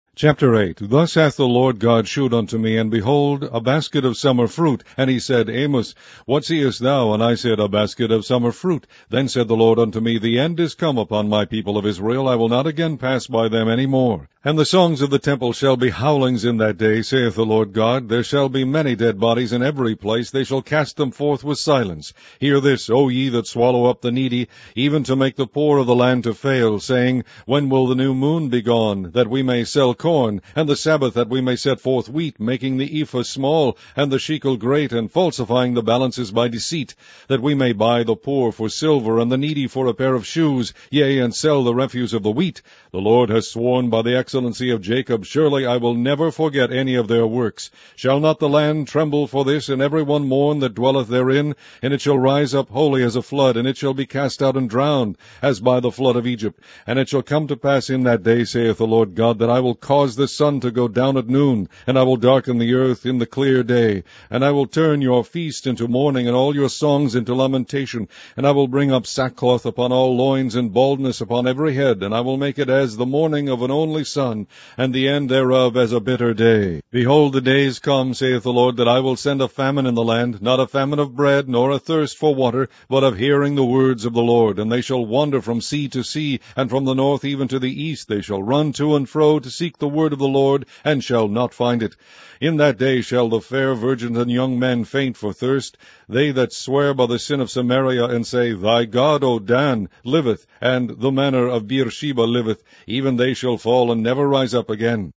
Online Audio Bible - King James Version - amos